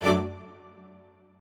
admin-leaf-alice-in-misanthrope/strings34_1_006.ogg at main